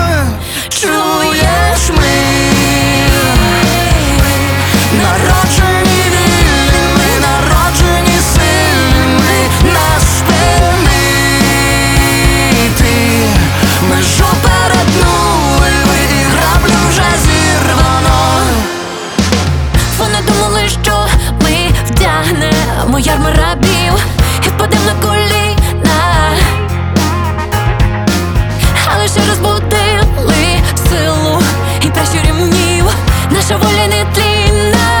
Жанр: Рок / Украинские